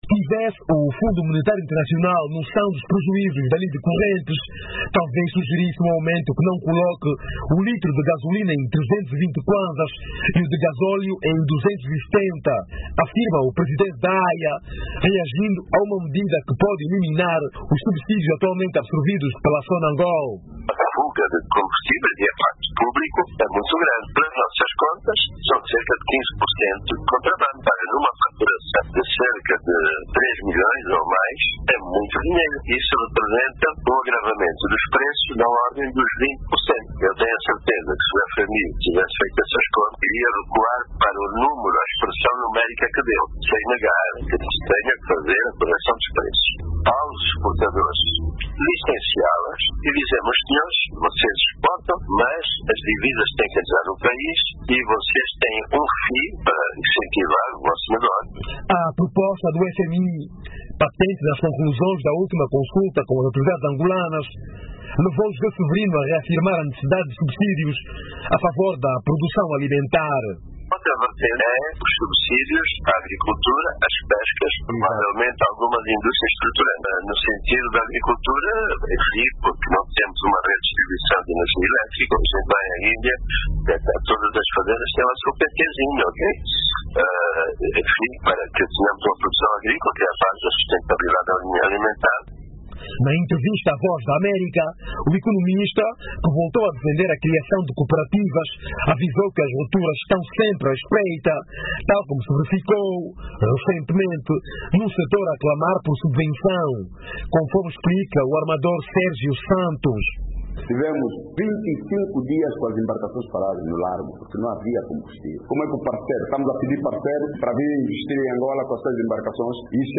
Em entrevista à VOA